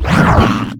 hurt3.ogg